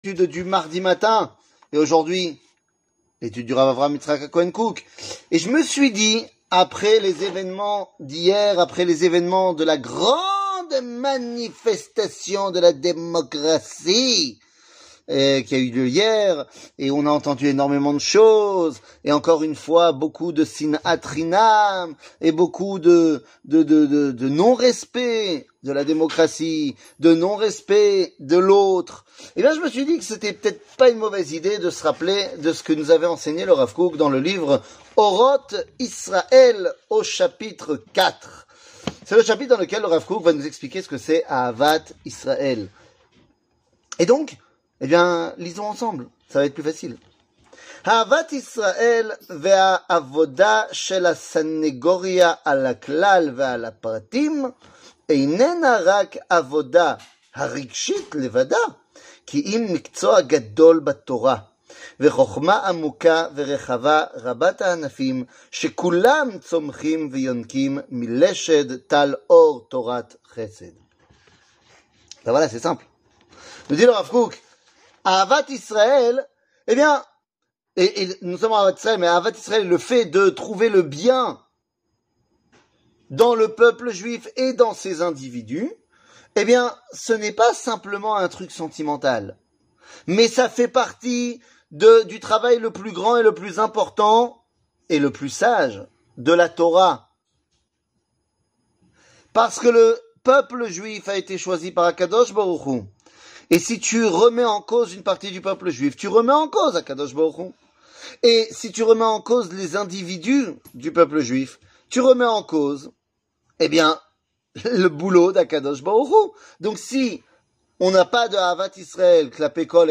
Rav Kook, Aavat Israël, Orot Israël 4,1 00:05:35 Rav Kook, Aavat Israël, Orot Israël 4,1 שיעור מ 14 פברואר 2023 05MIN הורדה בקובץ אודיו MP3 (5.11 Mo) הורדה בקובץ וידאו MP4 (10.87 Mo) TAGS : שיעורים קצרים